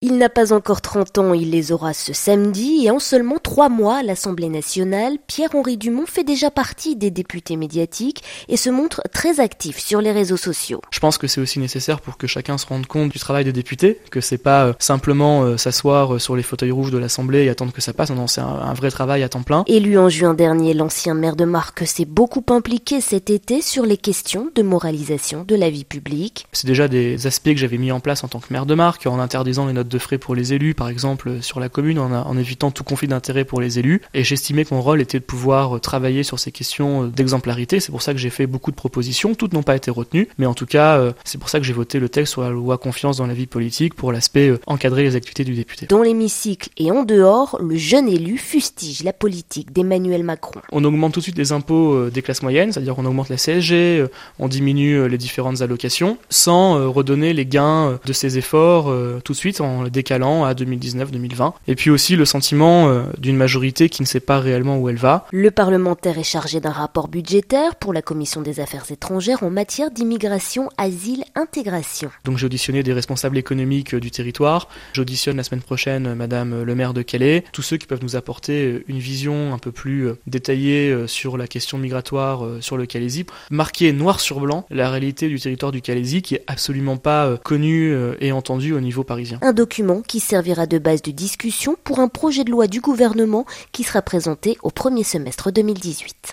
Pierre-Henri Dumont, le député de la 7ème circonscription du Pas-de-Calais, a tenu une conférence de presse de rentrée en fin de semaine dernière.